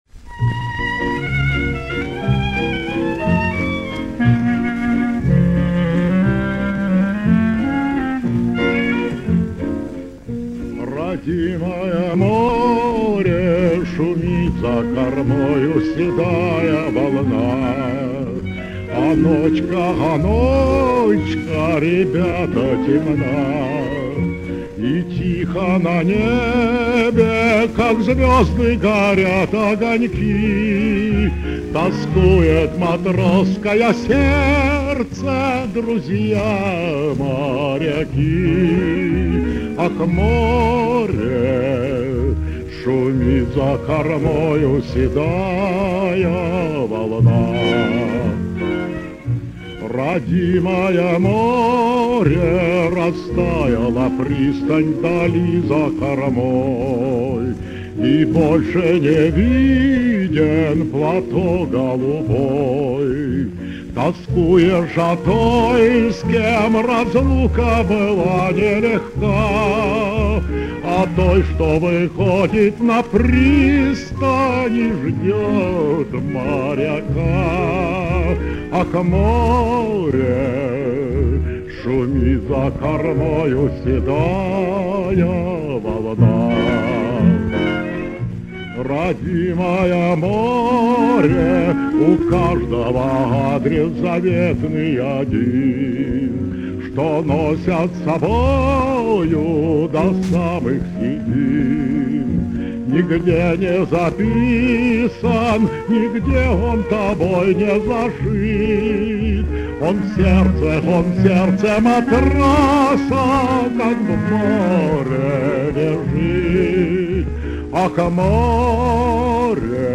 Душещипательная морская песня.